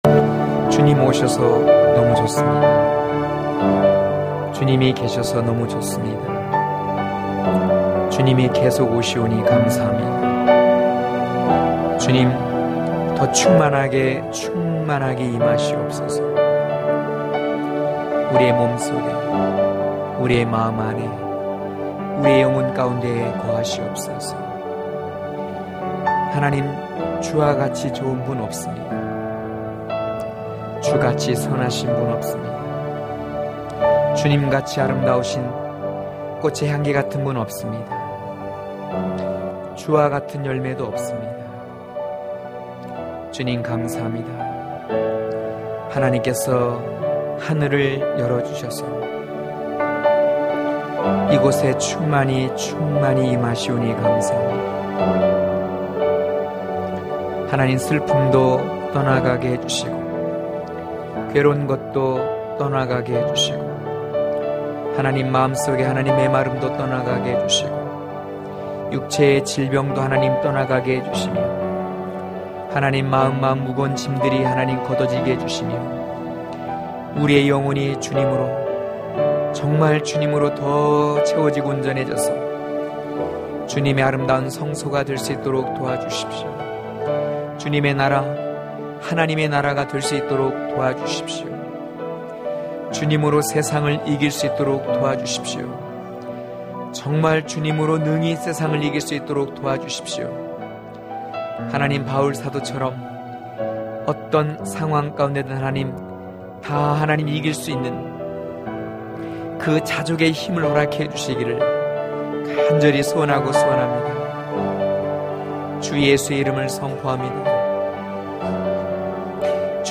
강해설교 - 12.열린 에덴의 신부(아4장7-16절)